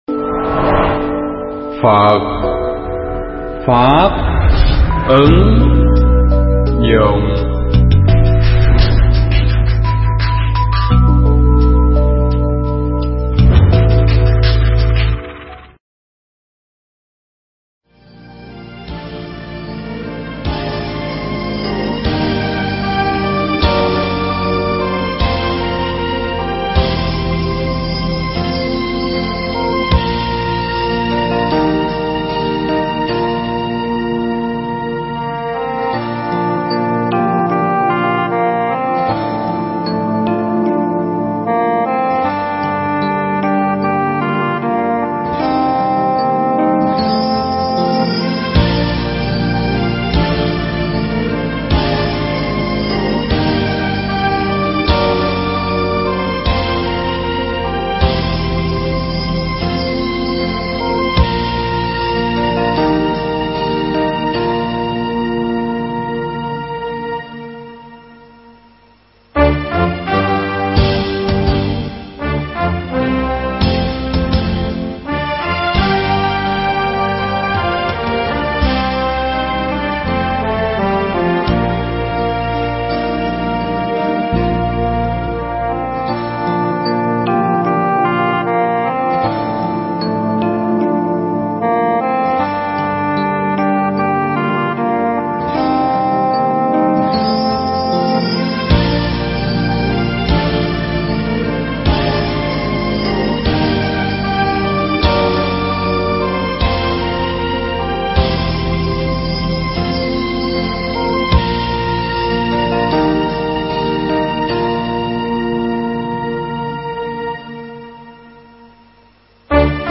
Mp3 Thuyết pháp Biết Đủ Trong Đạo Phật